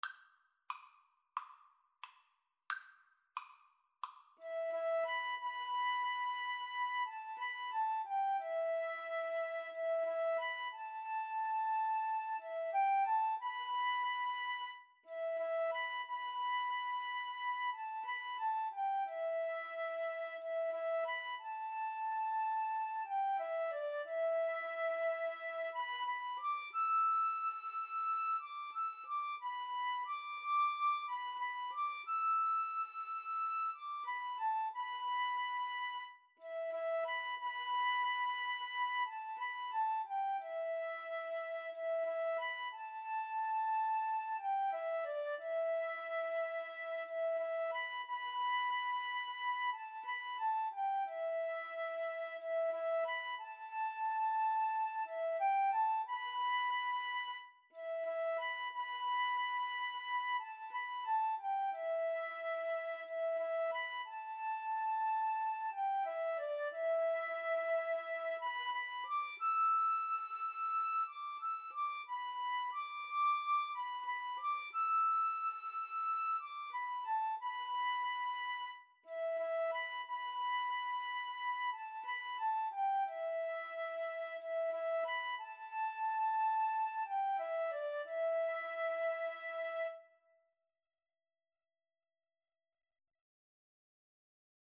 Andante = c. 90